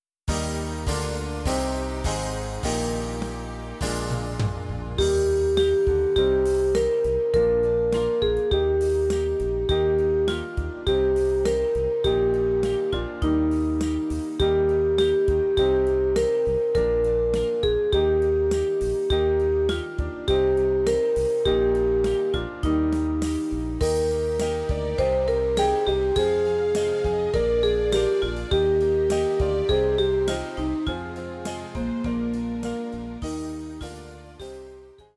Gattung: Weihnachtsmusik für Trompete (inkl. Audiodatei)
Besetzung: Instrumentalnoten für Trompete